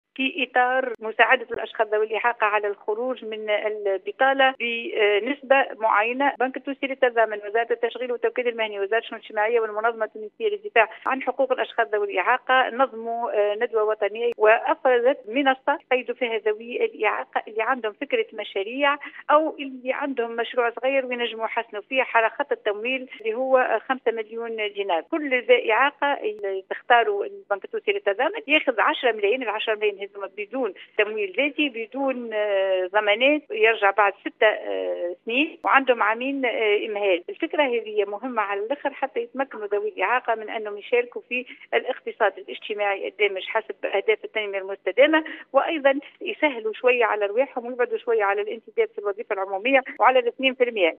خبر مسموع:قريبا سلسلة من الدورات التكوينية لمرافقة ذوي الاعاقة في بعث مشاريعهم الخاصة